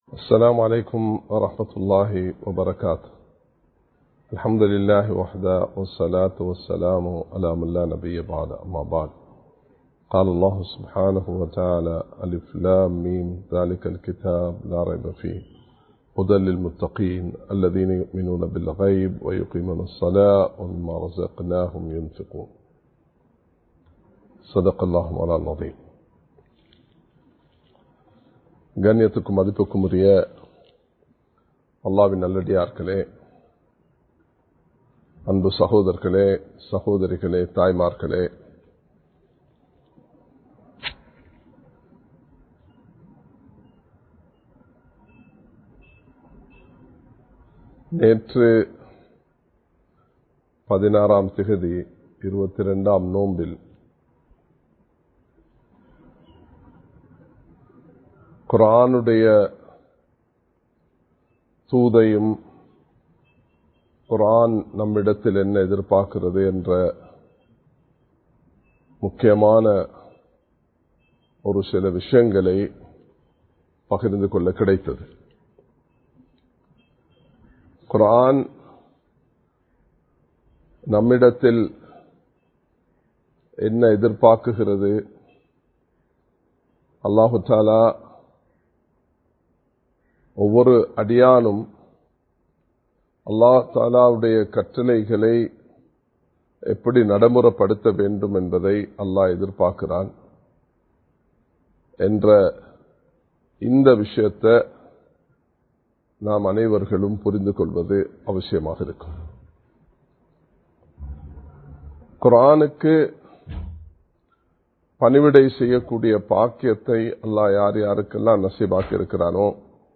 தக்வா (Part - 01) (Fear of Allah) | Audio Bayans | All Ceylon Muslim Youth Community | Addalaichenai
Live Stream